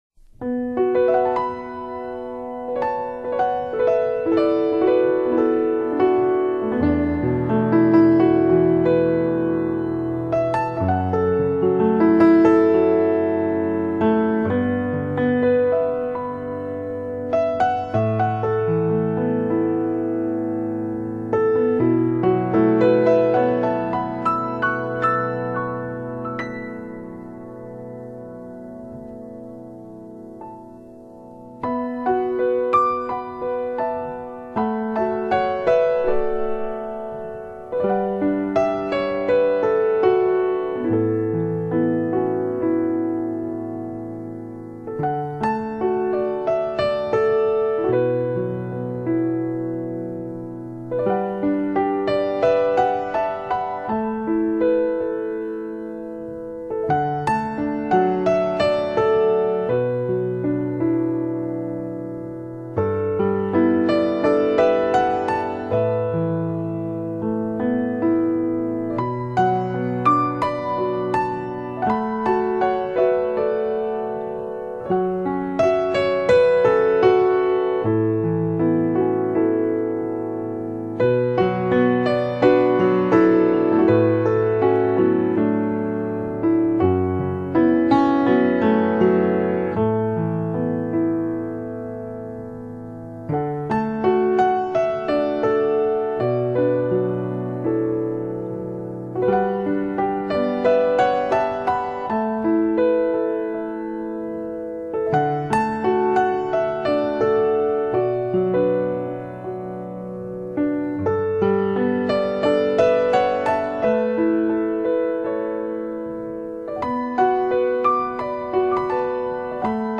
如歌琴声 淡淡幽情 唯美音符 思君如梦
声音靓绝 自然传真 真正示范级发烧三角名琴录音
晶莹剔透的美妙音符 聆听不曾离去的动人旋律